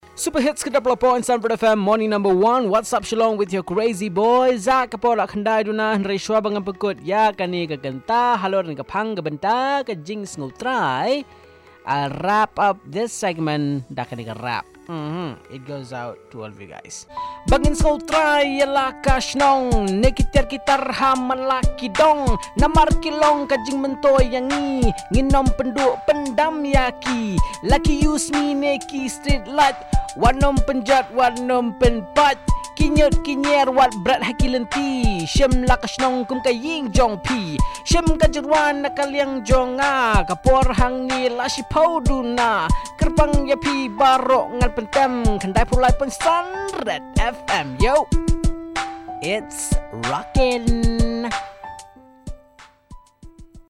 Rap on how to maintain public properties